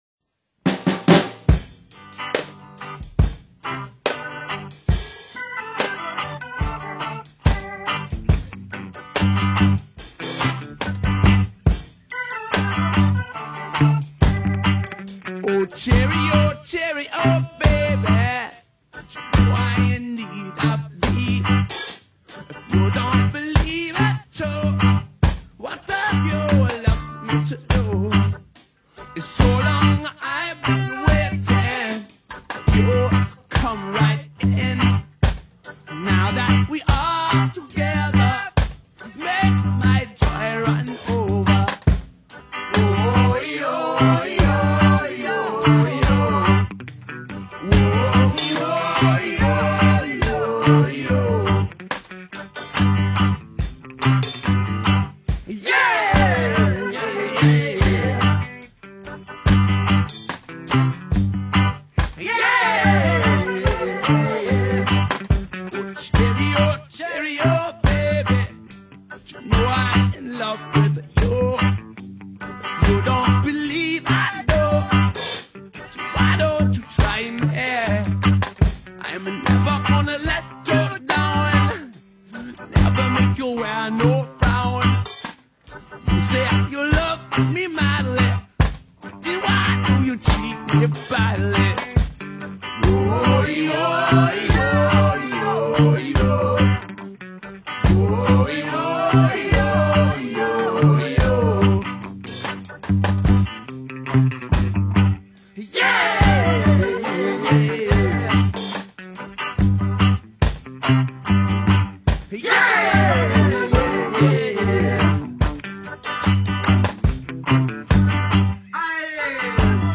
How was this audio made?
studio album